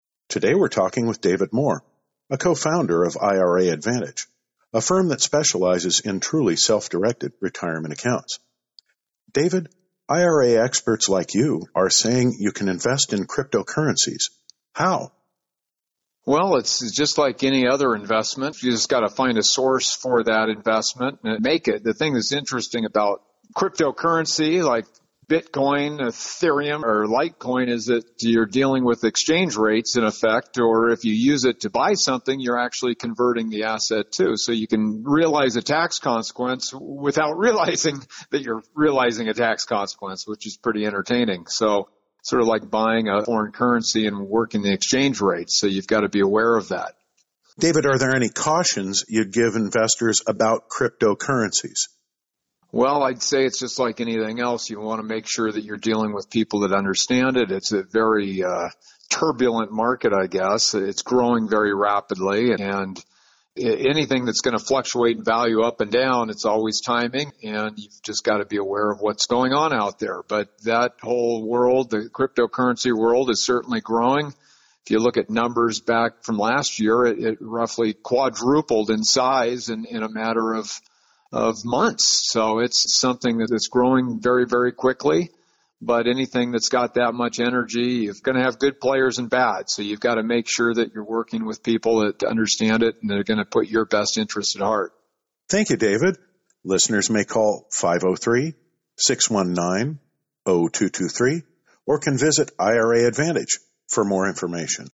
/ By / Blogcast, News